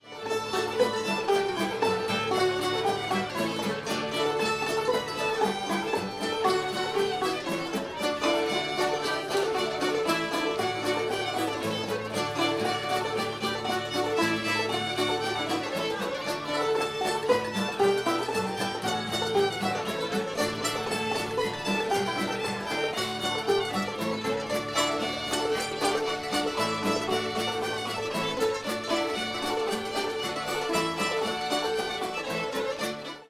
old molly hare [D]